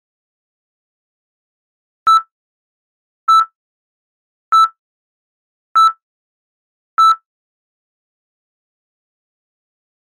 دانلود صدای قطع ضربان قلب 2 از ساعد نیوز با لینک مستقیم و کیفیت بالا
جلوه های صوتی